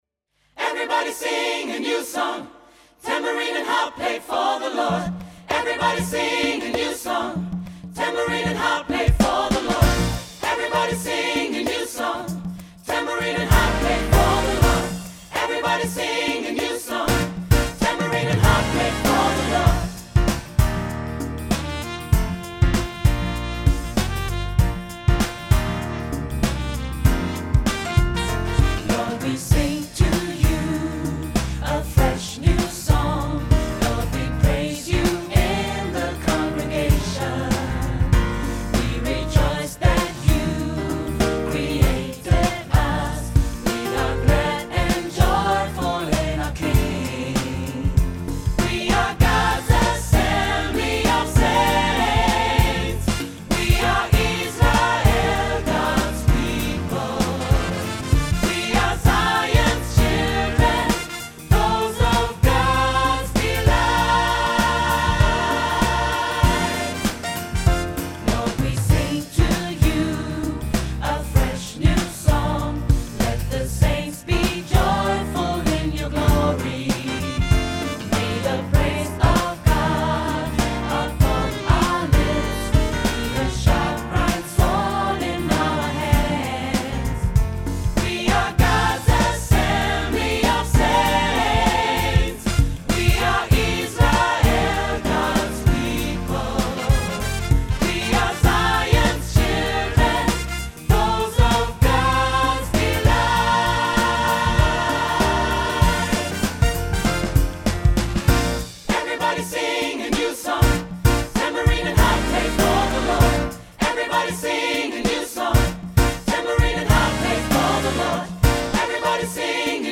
Hier erhalten Sie die Ausgabe mit dem Gesangssatz des Songs
• SAB, auch SSA + Piano